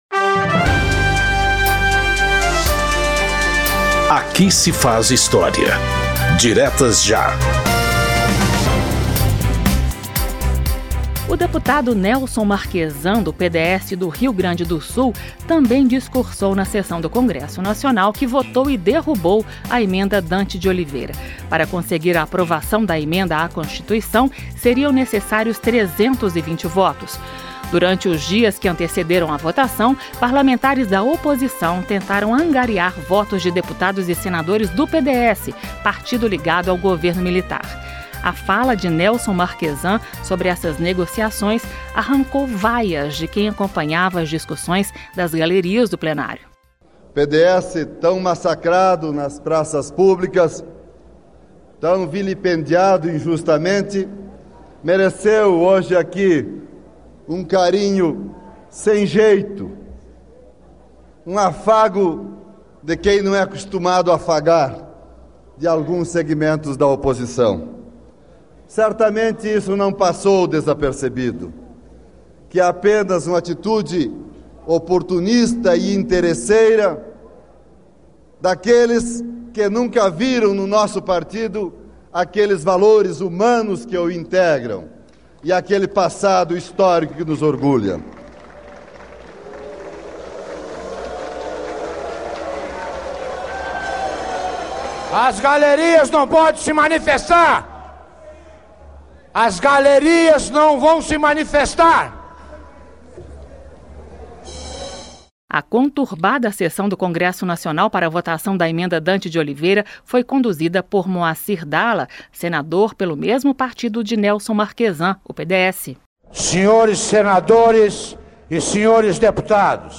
O deputado Nelson Marquezan, do PDS do Rio Grande do Sul, também discursou na sessão do Congresso Nacional que votou e derrubou a emenda Dante de Oliveira.
Um programa da Rádio Câmara que recupera pronunciamentos históricos feitos no Parlamento por deputados ou agentes públicos, contextualizando o momento político que motivou o discurso.
pgm-aqui-se-faz-histria-especial-40-anos-diretas-ja-discurso-nelson-marquezan-ed-006.mp3